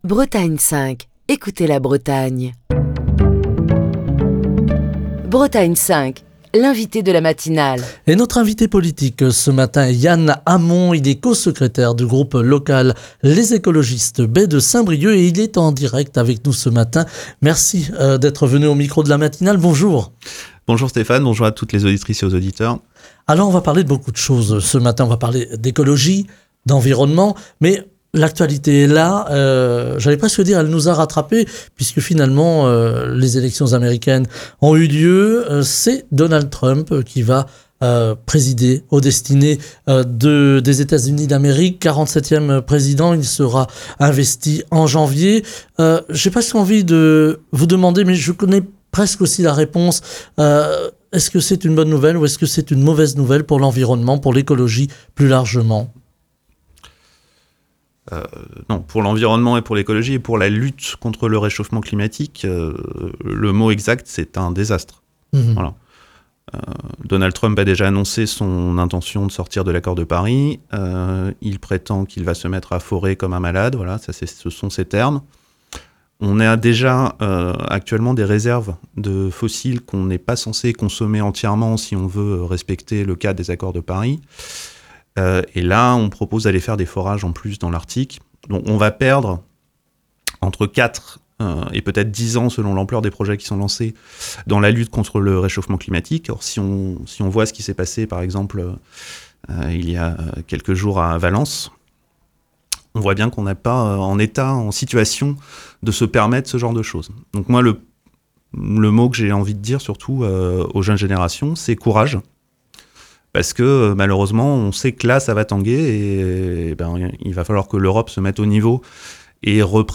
Émission du 8 novembre 2024.